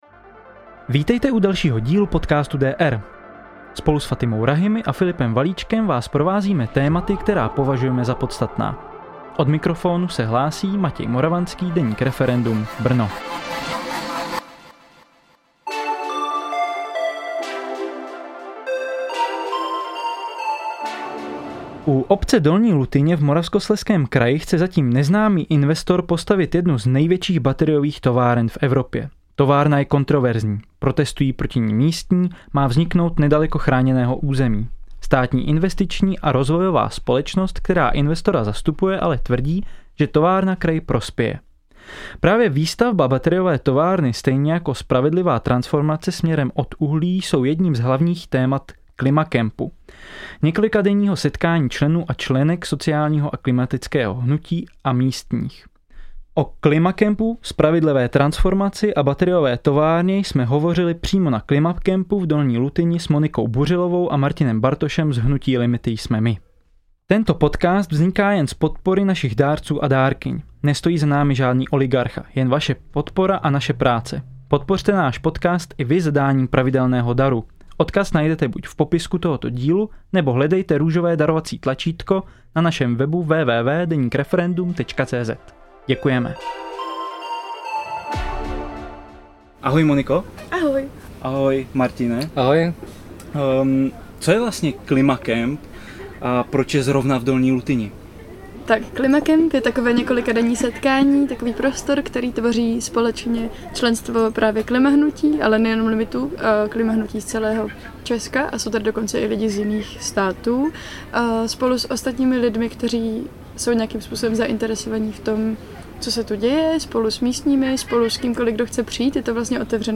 Epizodu jsme natáčeli přímo v terénu na klimakempu, proto omluvte případnou nižší zvukovou kvalitu nahrávky.